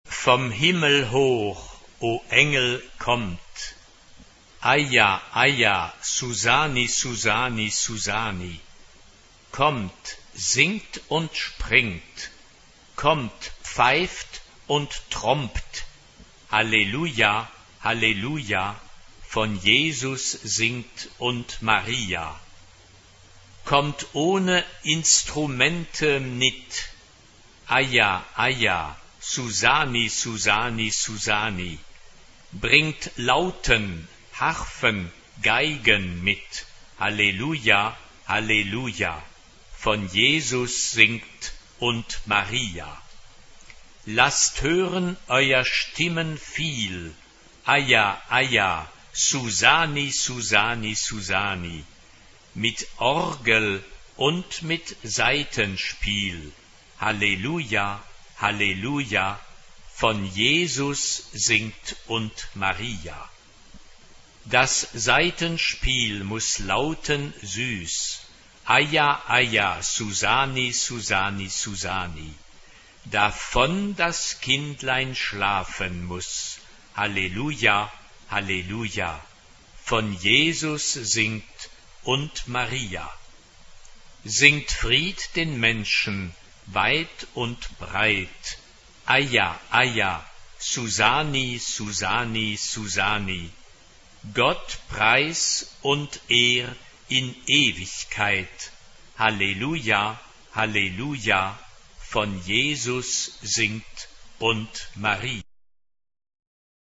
SSA (3 voix égales de femmes) ; Partition complète.
Sacré. Chœur.
Caractère de la pièce : vivant
Instrumentation : Clavier (1 partie(s) instrumentale(s))
Tonalité : fa majeur